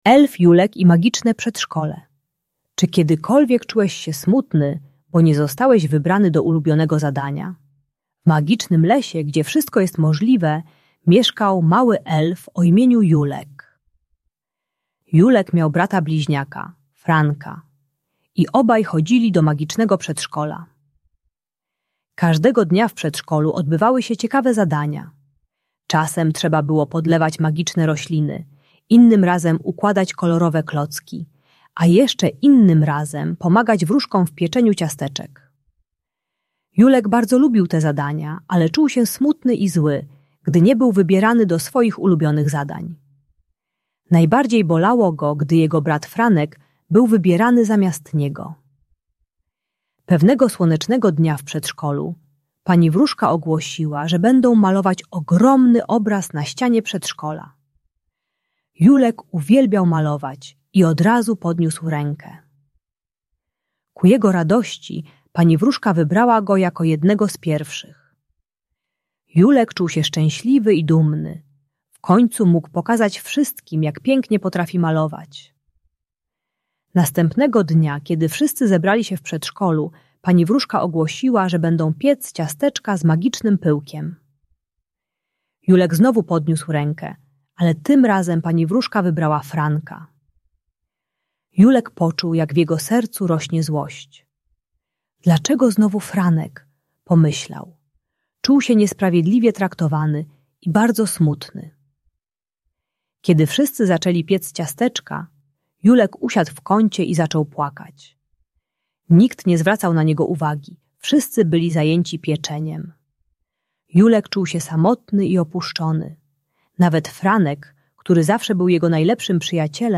Elf Julek i Magiczne Przedszkole - Bunt i wybuchy złości | Audiobajka